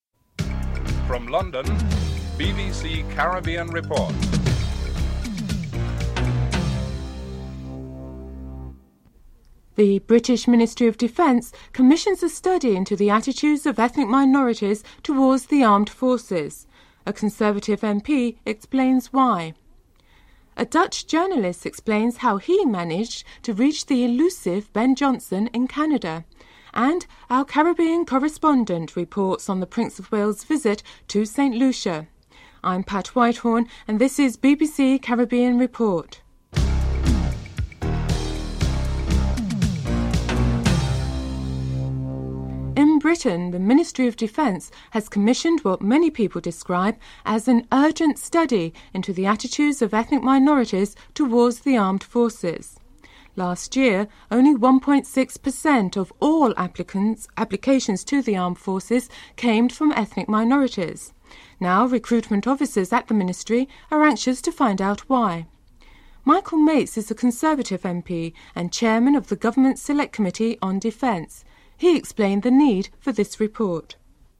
Michael Mates, Conservative MP and Chairman of the Government Select Committee on Defence explains the need for a study on attitudes of ethnic minorities toward the Armed Forces.
Interviews are held with athletes, Linford Christie, Colin Jackson and Michael Rosswess at the European Athletics Indoor Championships in Holland.